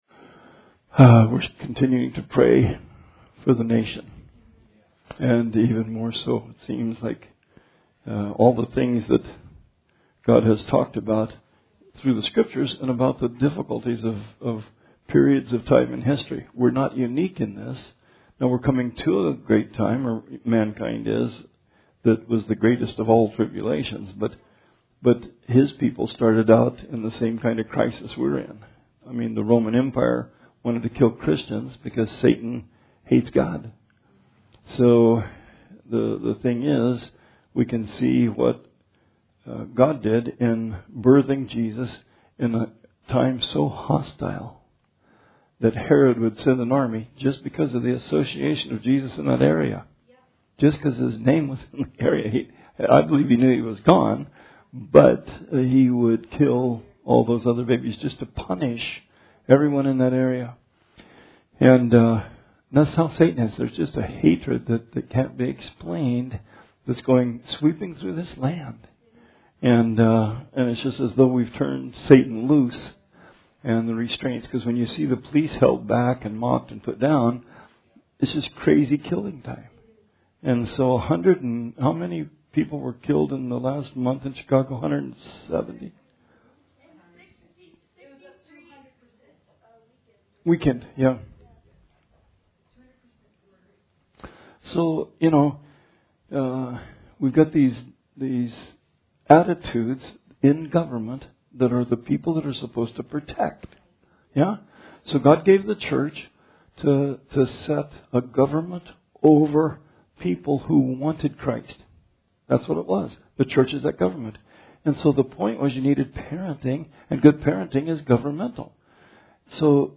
Bible Study 7/22/20